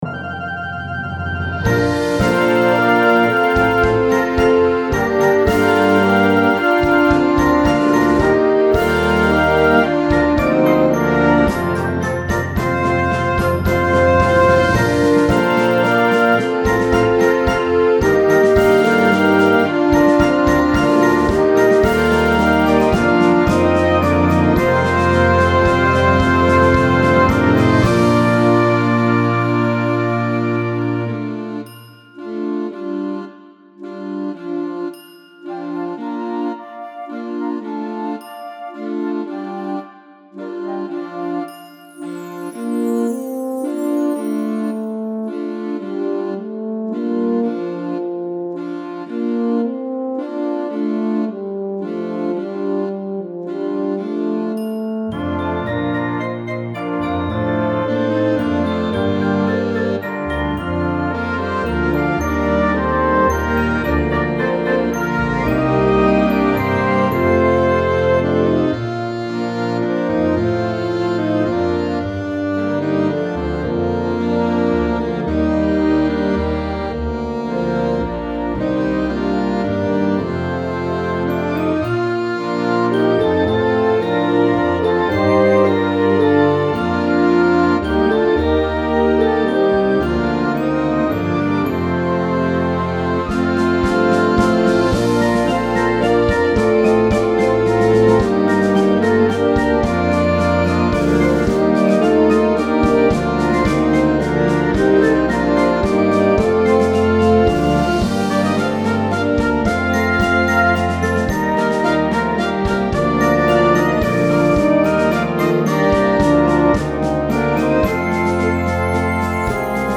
Concert Band – Grade 3